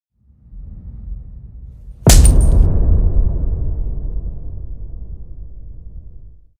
chain-break.mp3